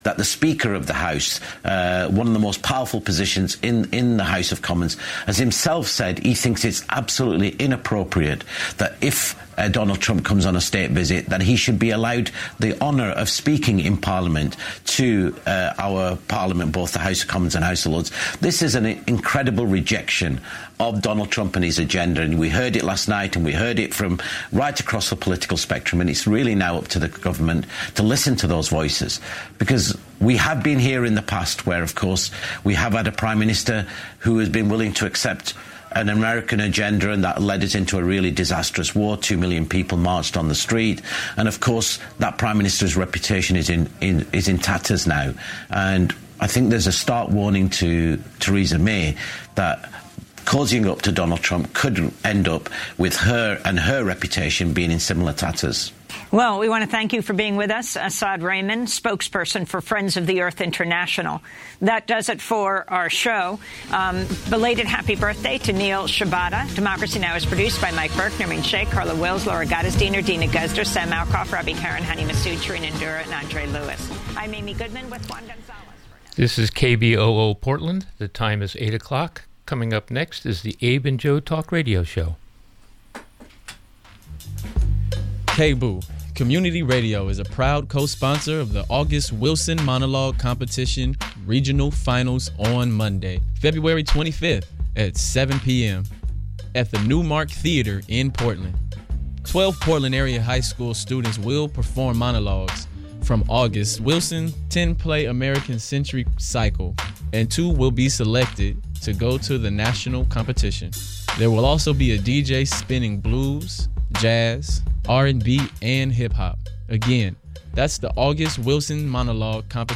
Talk Radio Show